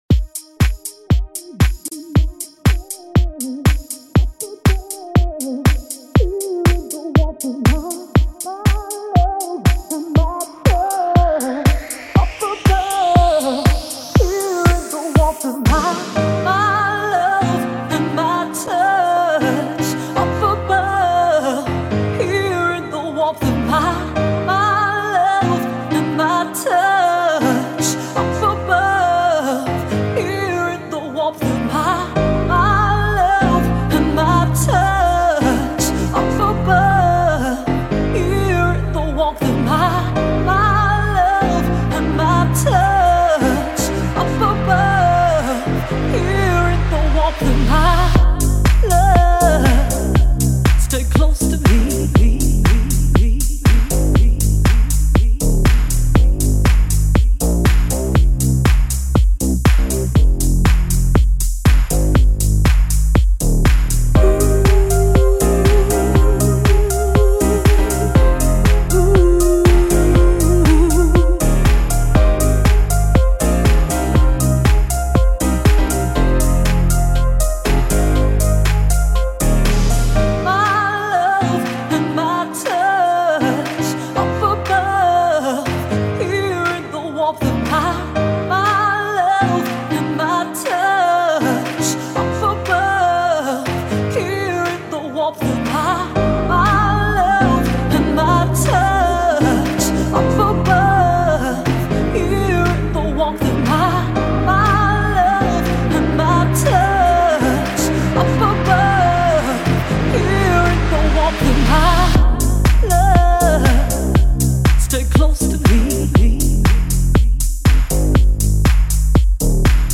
vocalist
DJ & percussionist